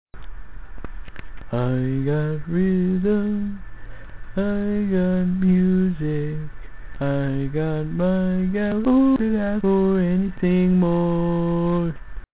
Key written in: D♭ Major
Each recording below is single part only.